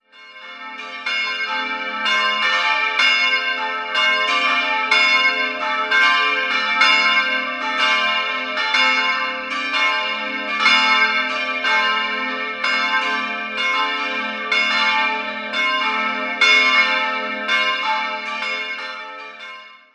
3-stimmiges TeDeum-Geläute: a'-c''-d''